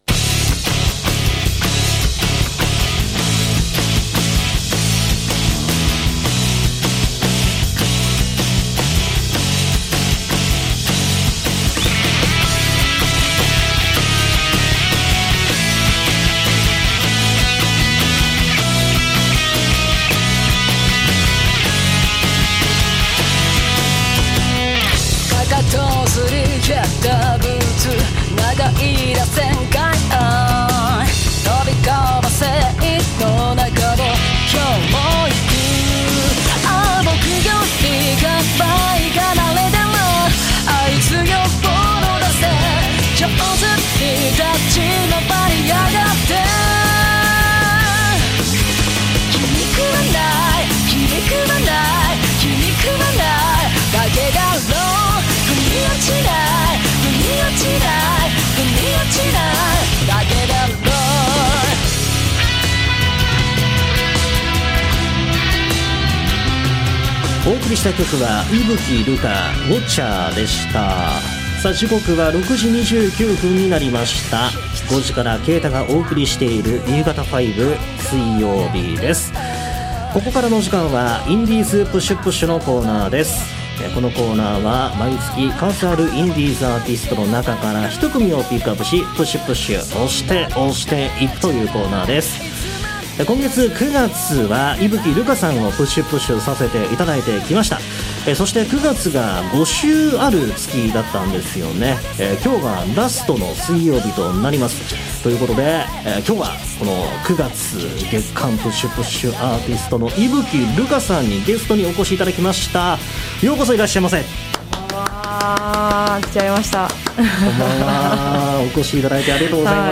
＜放送同録＞